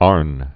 (ärn), Thomas Augustine 1710-1778.